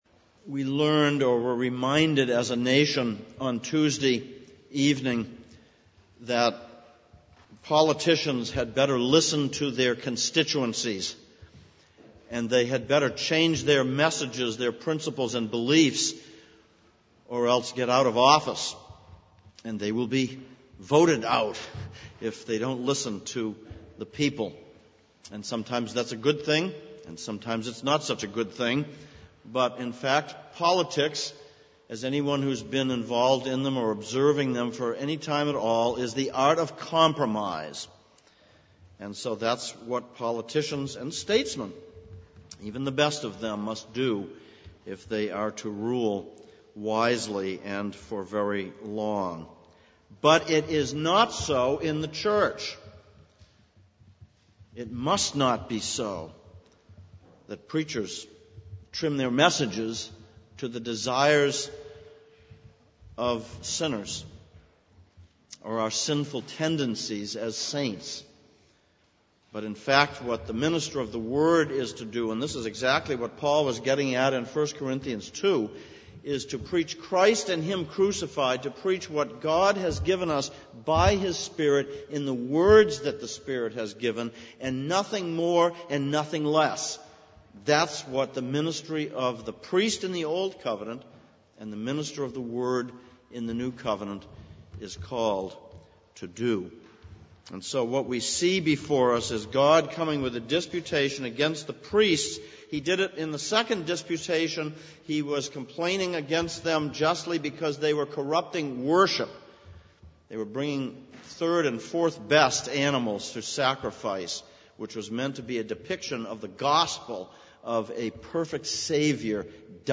Exposition of Malachi Passage: Malachi 2:1-9, 1 Corinthians 2:1-16 Service Type: Sunday Morning Malachi 2:1-9